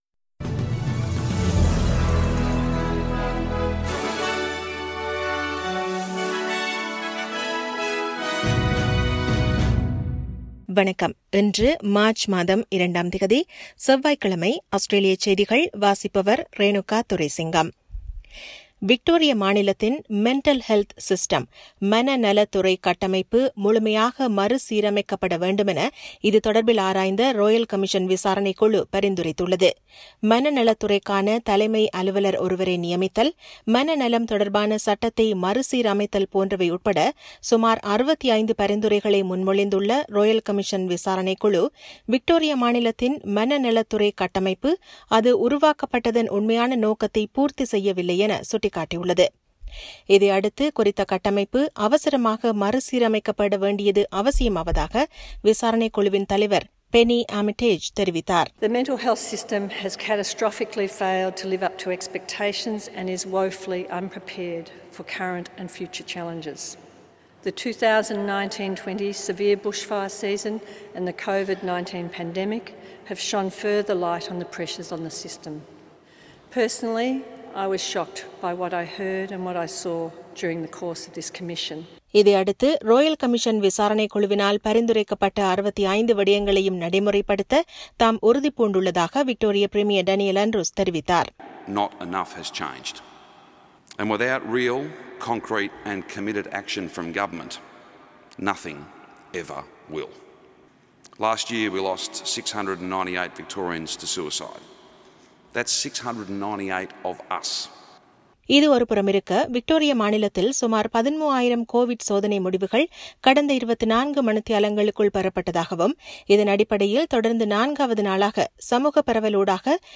Australian news bulletin for Tuesday 02 March 2021.